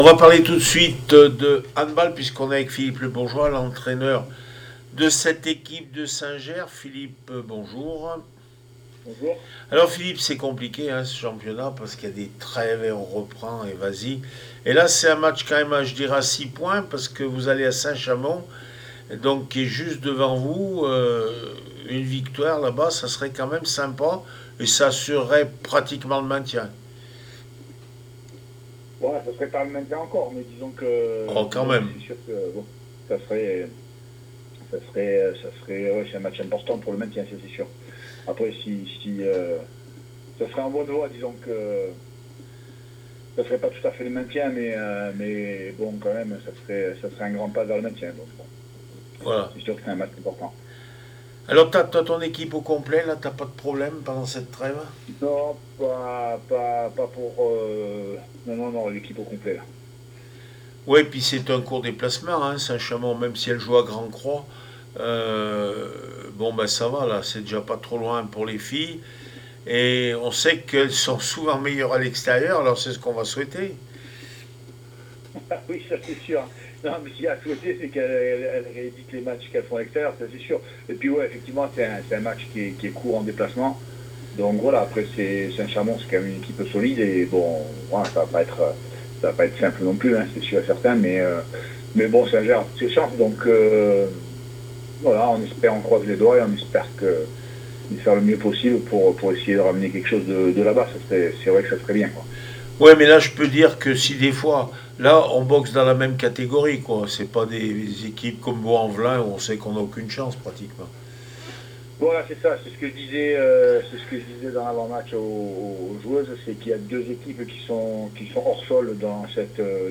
18 avril 2026   1 - Sport, 1 - Vos interviews